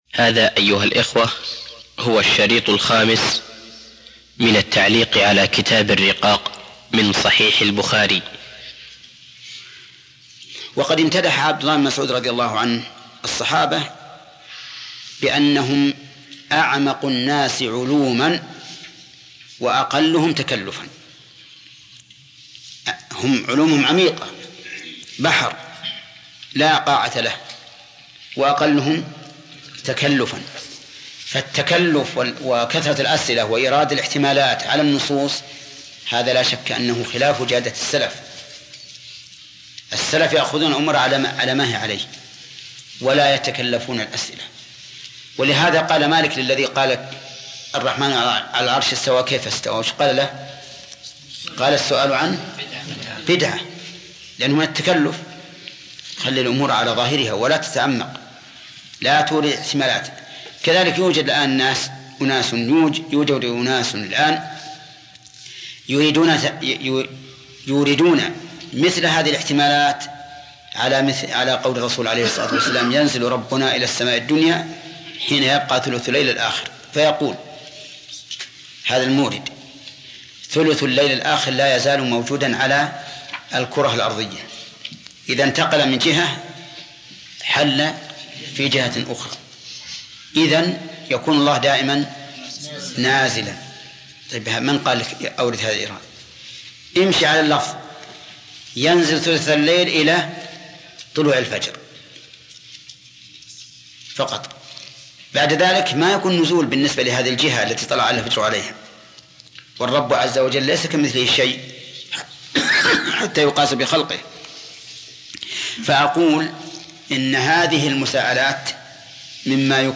الدرس الخامس - التعليق على كتاب الرقاق و القدر من صحيح البخاري - فضيلة الشيخ محمد بن صالح العثيمين رحمه الله
عنوان المادة الدرس الخامس - التعليق على كتاب الرقاق و القدر من صحيح البخاري تاريخ التحميل الأثنين 21 اكتوبر 2013 مـ حجم المادة غير معروف عدد الزيارات 923 زيارة عدد مرات الحفظ 324 مرة إستماع المادة حفظ المادة اضف تعليقك أرسل لصديق